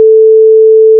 sine.wav